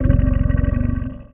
Sfx_creature_spikeytrap_idle_os_05.ogg